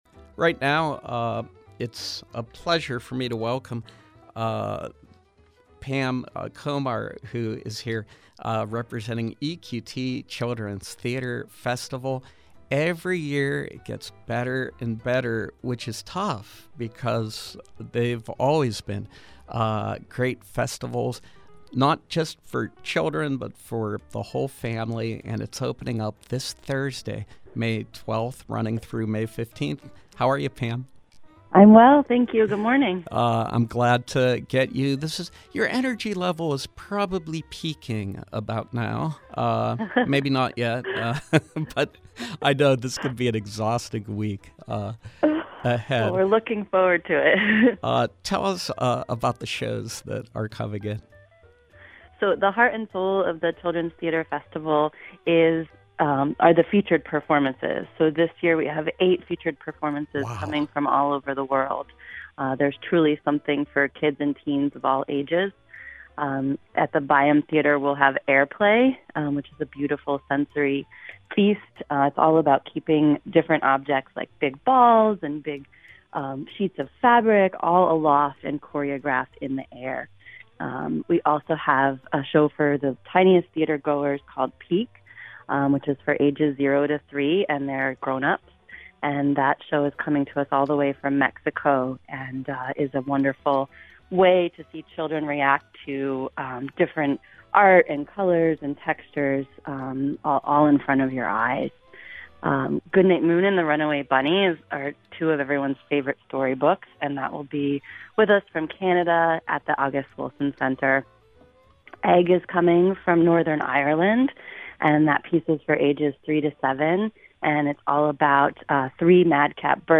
Interview: EQT Children’s Theater Festival